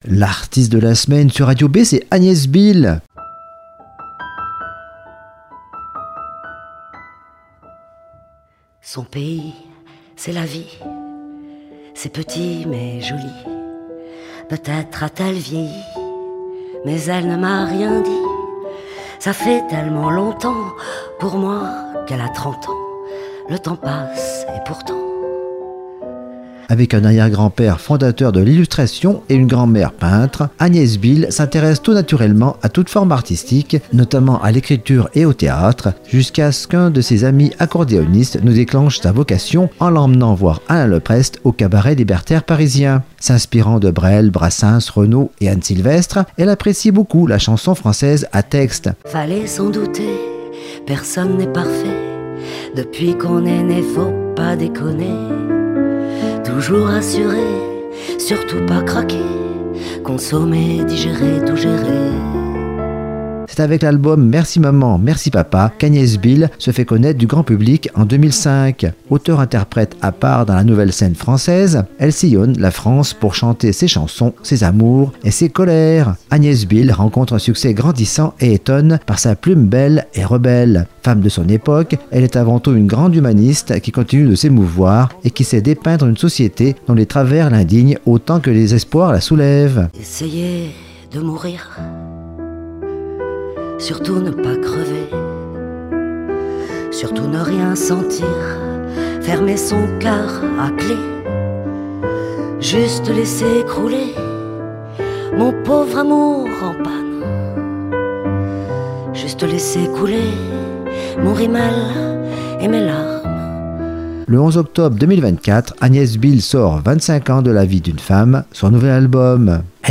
S'inspirant de Brel, Brassens, Renaud et Anne Sylvestre, elle apprécie beaucoup la chanson française "à texte".
Pas de propos empesés, académiques, mais un phrasé populaire et sensible, un vocabulaire au plus près du vécu et des possibles humeurs, de la déprime même.
Agnès BIHL, tour à tour intimiste, émouvante, drôle et espiègle.